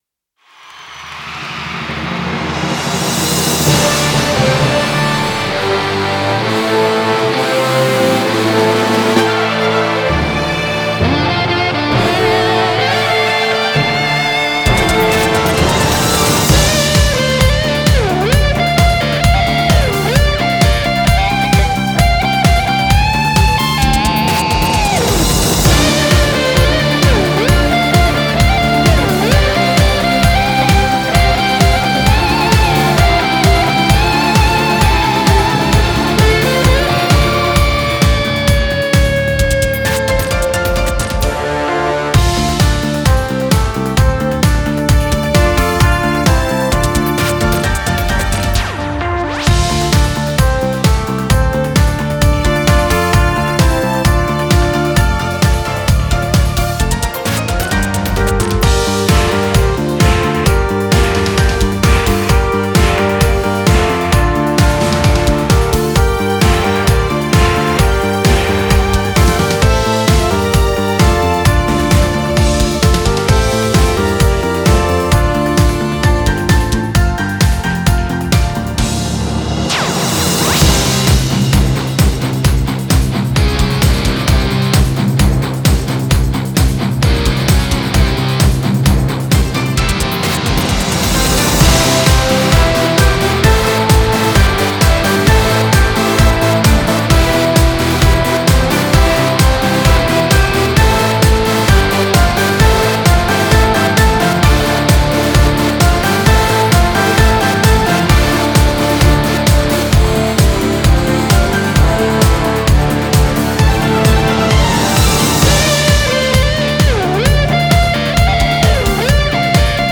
03-INSTRUMENTAL
Ηλεκτρική Κιθάρα